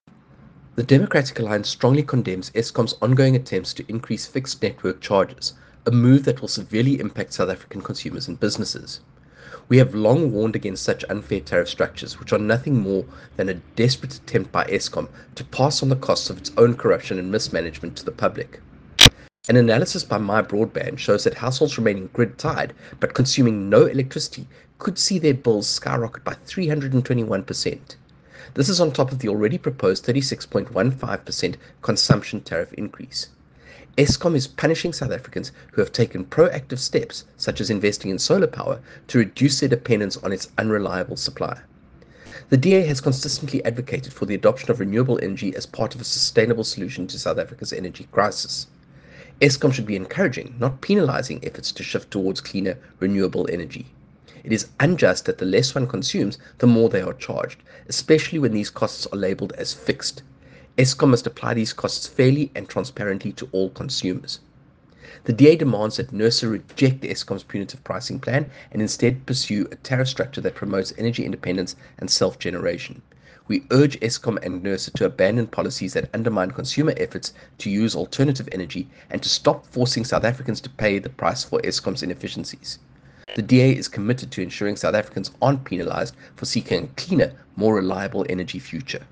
soundbite by Kevin Mileham MP.